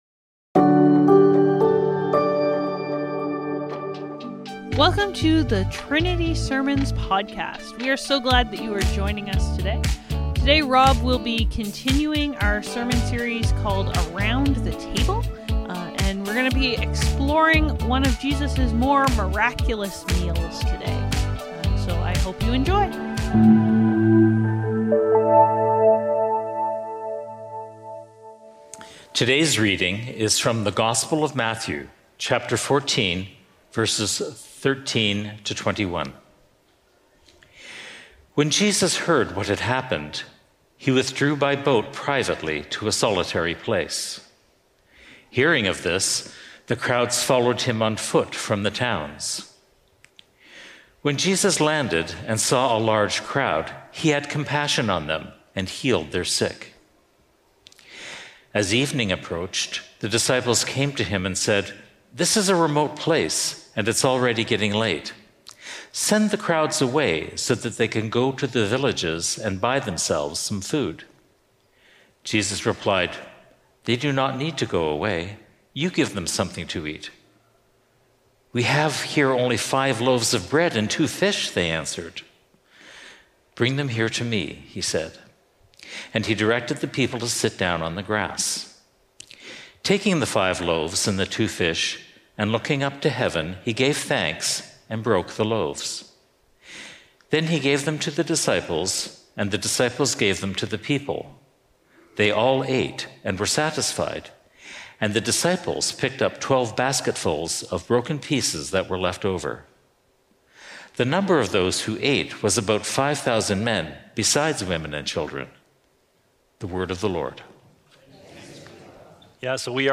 Trinity Streetsville - From a Little to a Lot! | Around The Table | Trinity Sermons